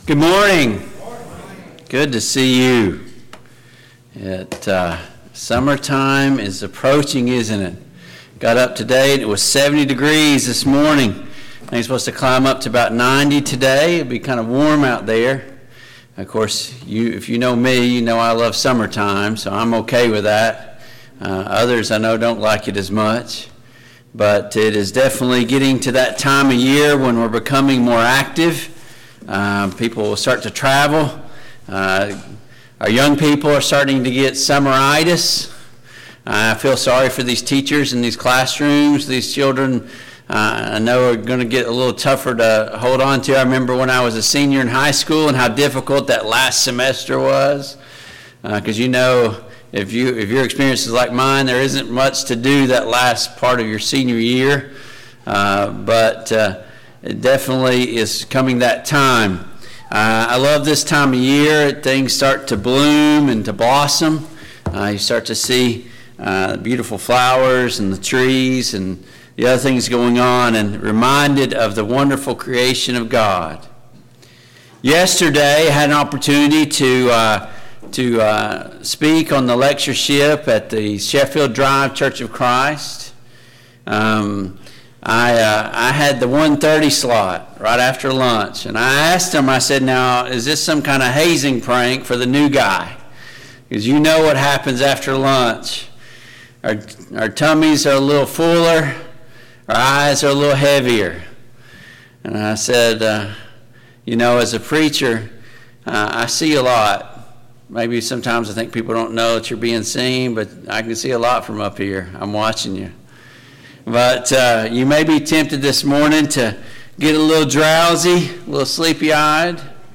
Service Type: AM Worship Topics: Growing in our faith , Growth